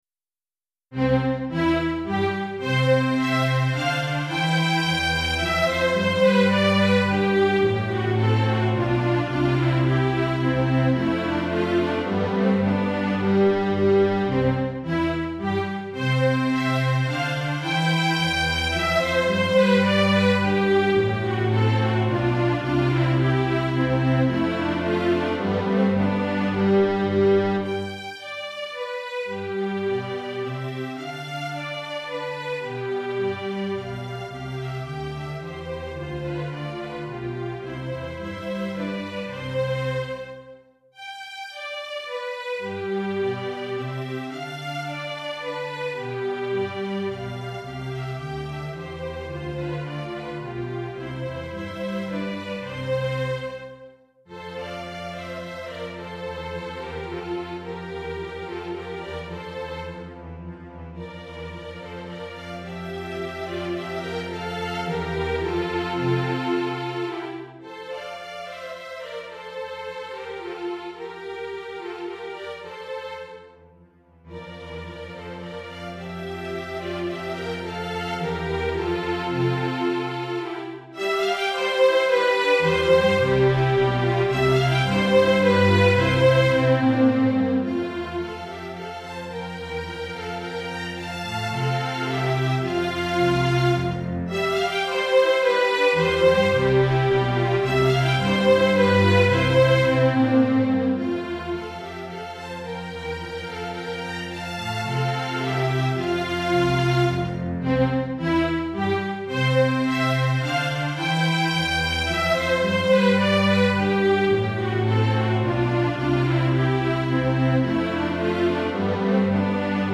Répertoire pour Musique de chambre - Violon 1
Violon 2
Alto